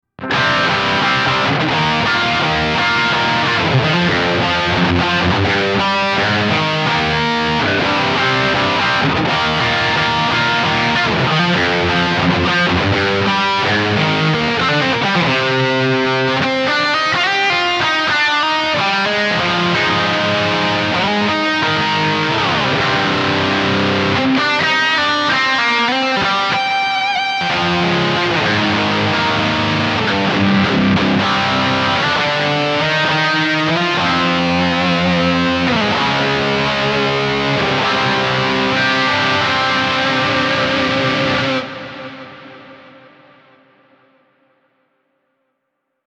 This Amp Clone rig pack is made from a SLO II Synergy Module with a matching cab.
A. DRIVE_LEAD - Scene to switch between a drive and lead sound
RAW AUDIO CLIPS ONLY, NO POST-PROCESSING EFFECTS